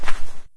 default_dirt_footstep.2.ogg